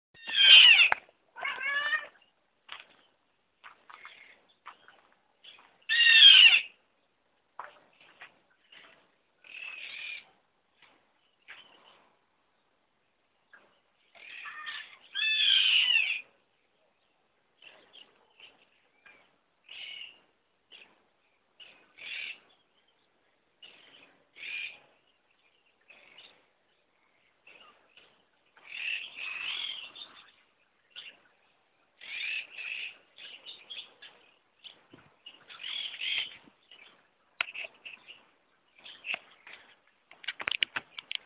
Chimango Caracara (Daptrius chimango)
Life Stage: Juvenile
Province / Department: Buenos Aires
Condition: Wild
Certainty: Recorded vocal